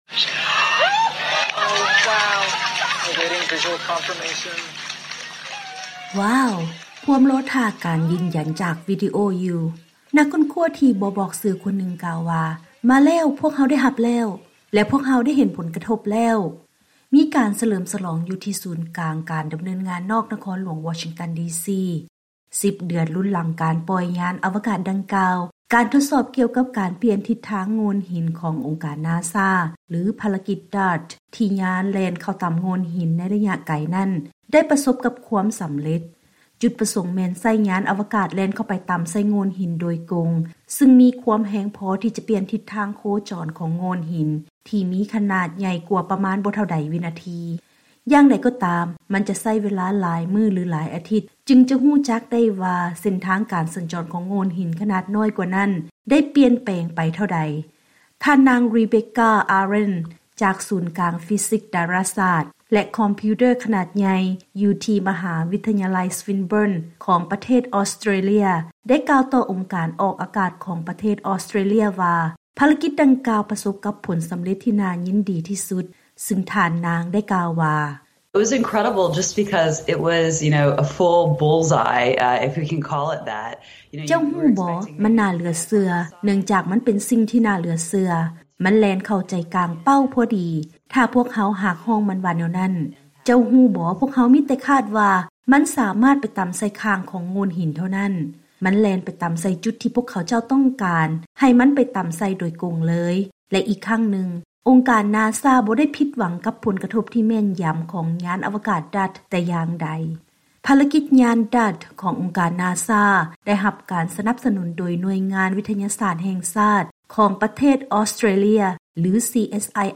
MISSION CONTROL ACT. EST AND FADE